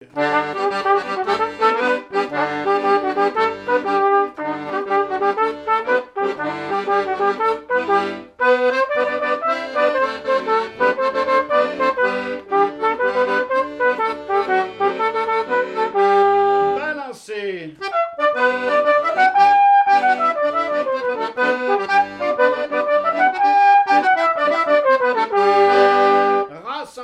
danse : branle : avant-deux
airs de danses issus de groupes folkloriques locaux
Pièce musicale inédite